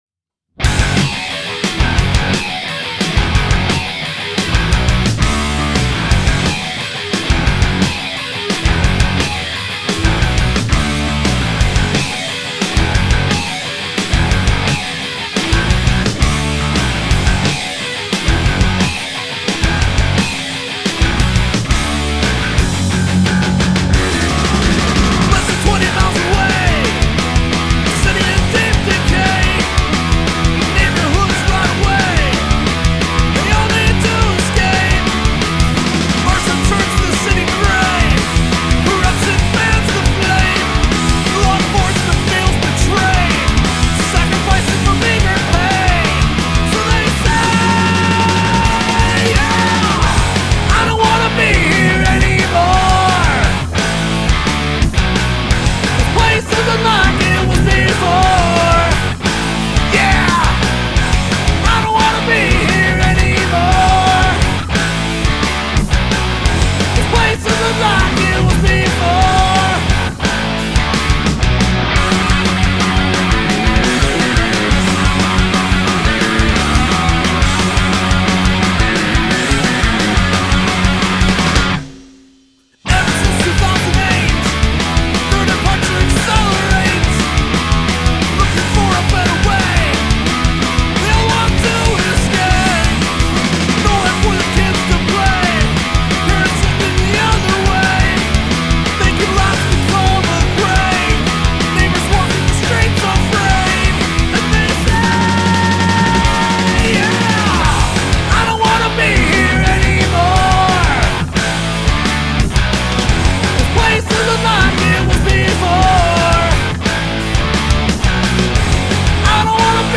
Studio Drums